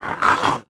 khanat-sounds-sources/_stock/sound_library/animals/monsters/mnstr11.wav at b47298e59bc2d07382d075ea6095eeaaa149284c